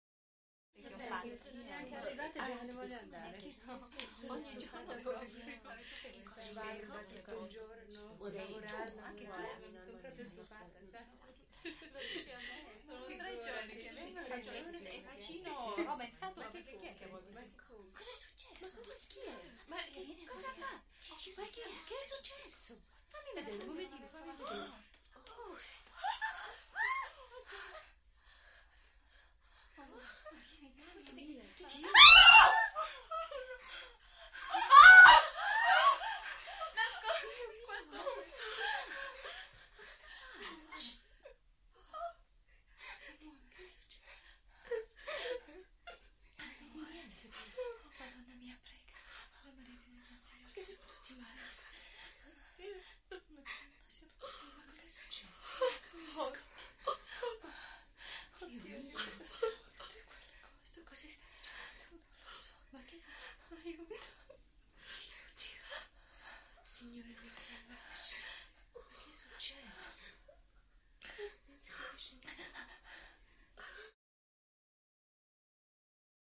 Interior Small Group Of Italian Women Chatters With A Few Light Screams & Sobbing.